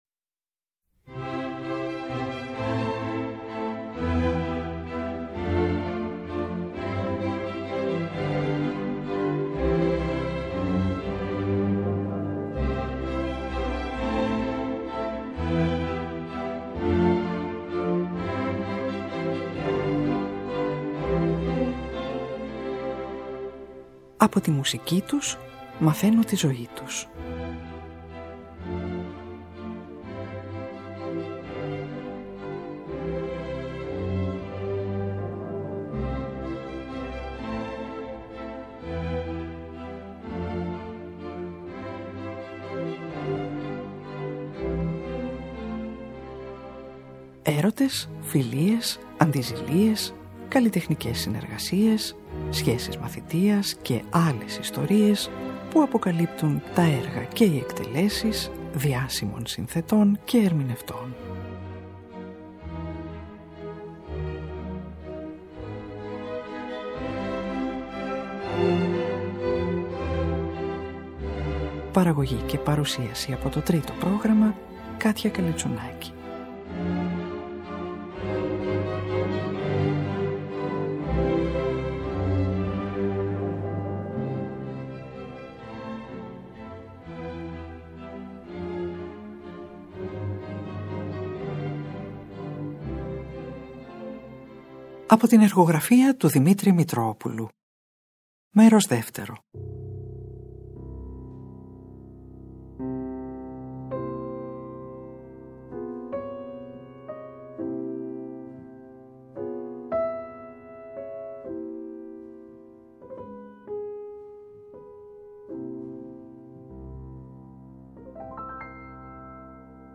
για βιολί και πιάνο